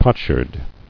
[pot·sherd]